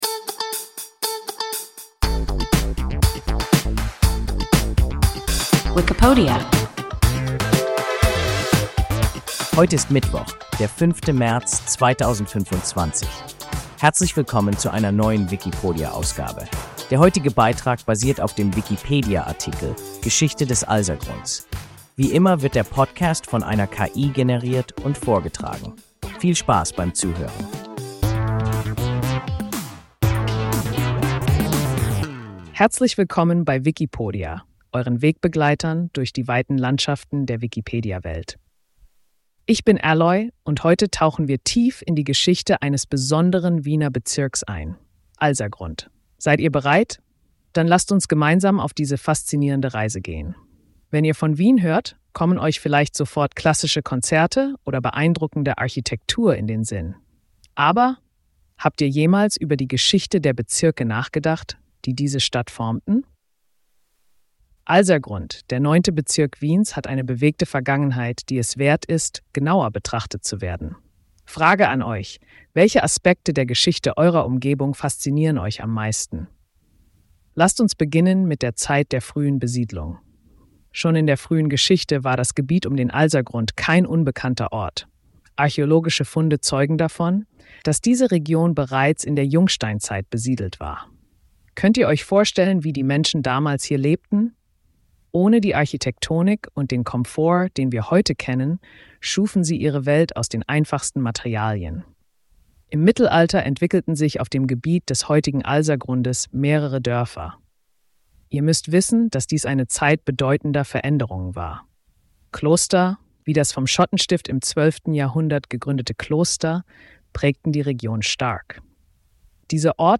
Geschichte des Alsergrunds – WIKIPODIA – ein KI Podcast